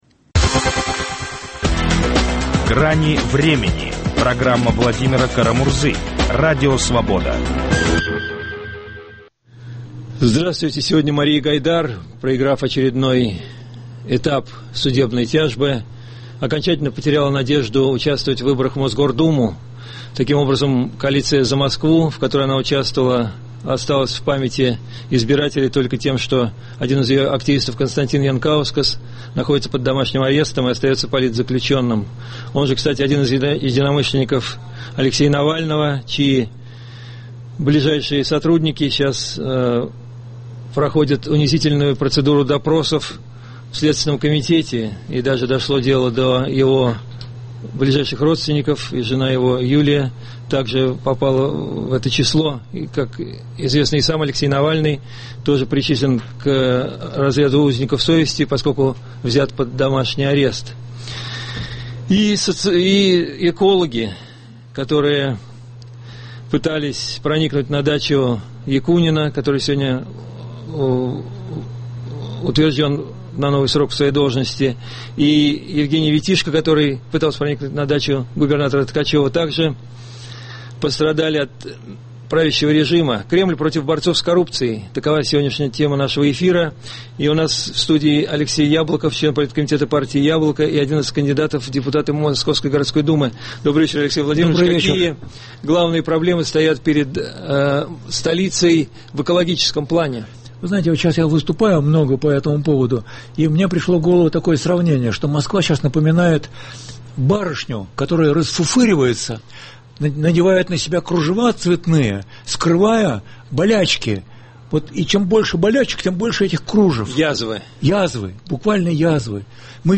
В разговоре принимают участие общественный активист Евгения Чирикова, глава фонда "Социальный запрос" Мария Гайдар, член политкомитета партии «Яблоко» Алексей Яблоков